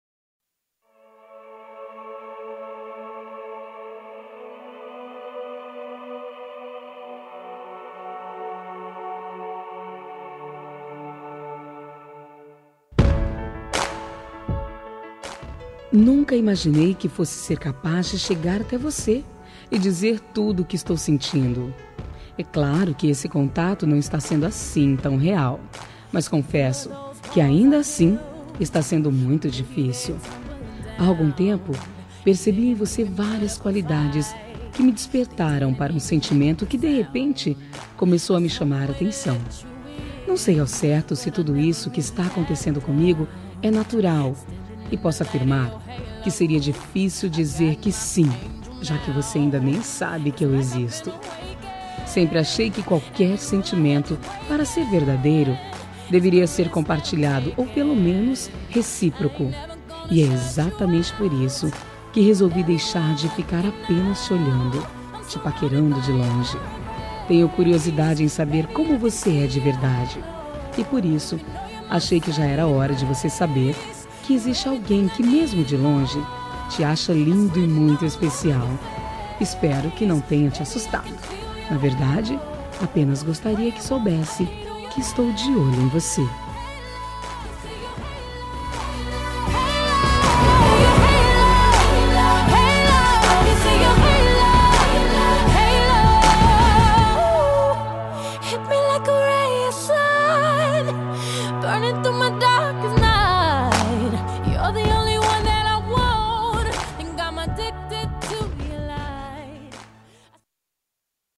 Telemensagem de Conquista – Voz Feminina – Cód: 140116